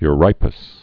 (y-rīpəs)